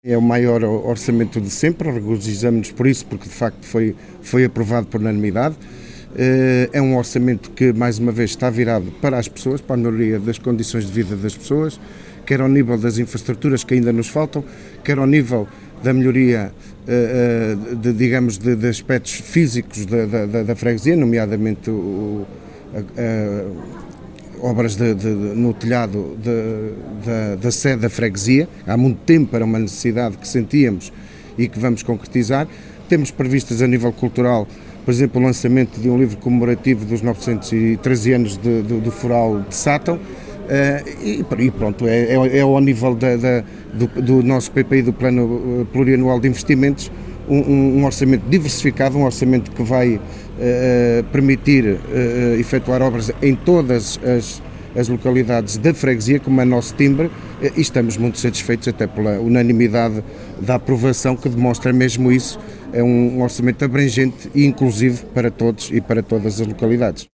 António José Carvalho, Presidente da Junta de Freguesia, em declarações à Alive FM, fala deste orçamento, dizendo que “é o maior de sempre…“, “um orçamento abrangente e inclusivo…“.
Antonio-J.-Carvalho-Pres.-Junta-de-Satao.mp3